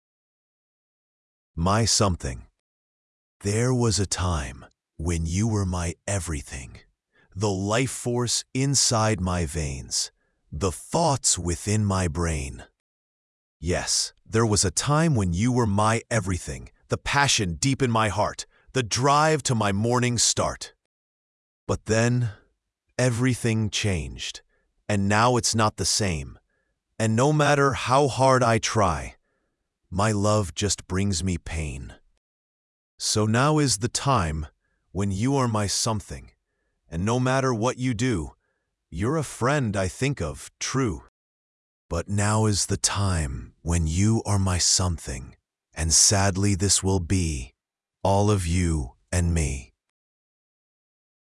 AI Examples / Reading a Sad Poem